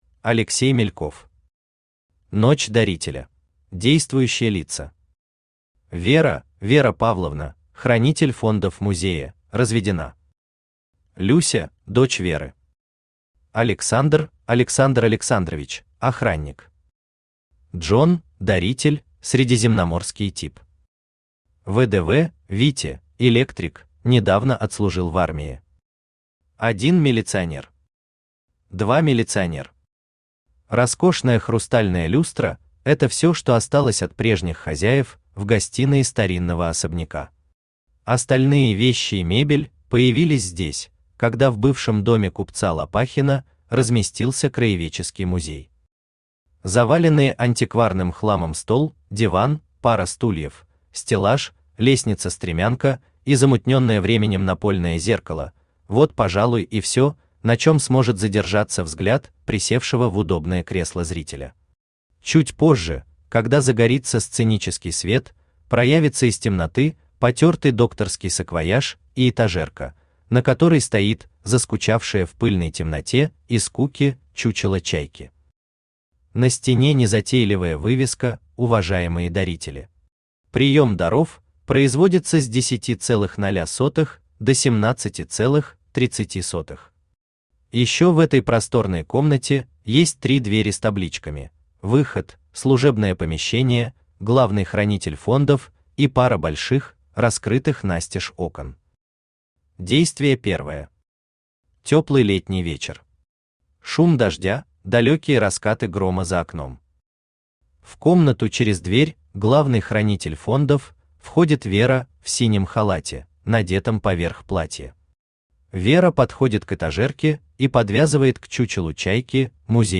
Aудиокнига Ночь дарителя Автор Алексей Николаевич Мельков Читает аудиокнигу Авточтец ЛитРес.